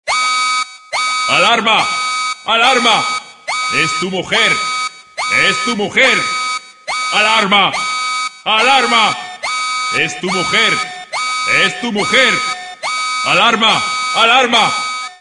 Efectos de sonido
SONIDOS GRACIOSOS
SONIDOS GRACIOSOS es un Tono para tu CELULAR que puedes usar también como efecto de sonido
Sonidos_Graciosos.mp3